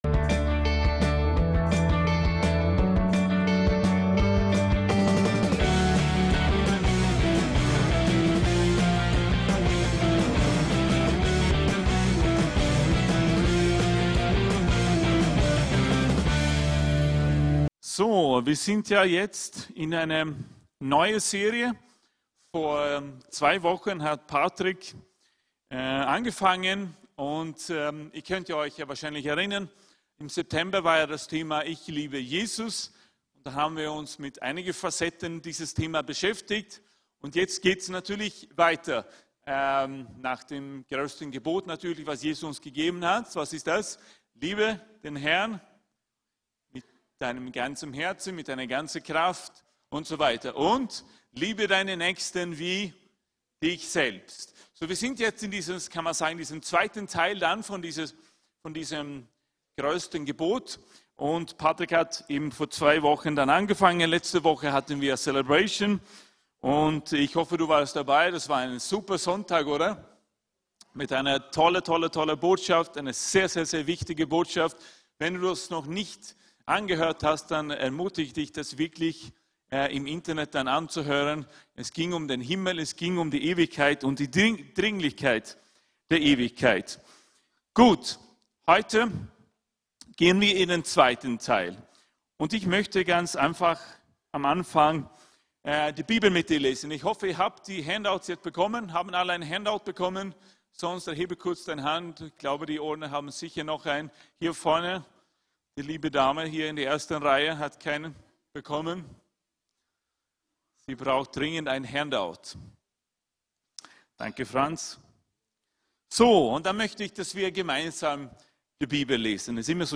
Gottesdienste